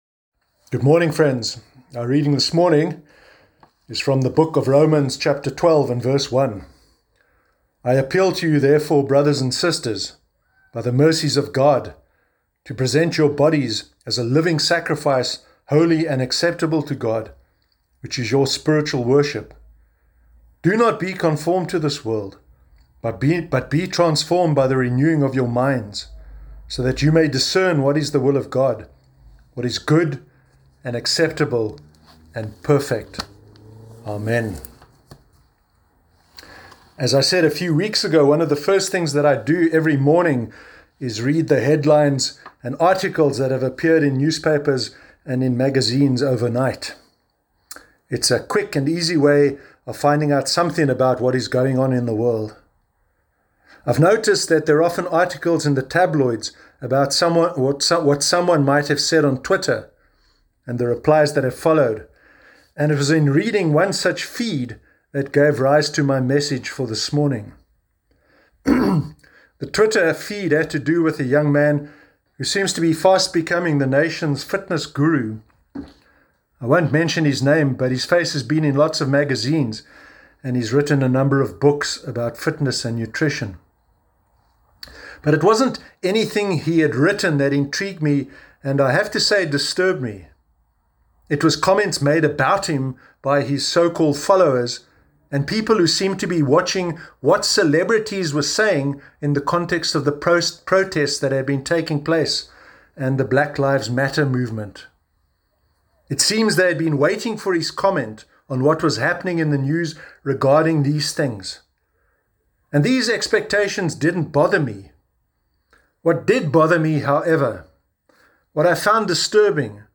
Sermon Sunday 28 June 2020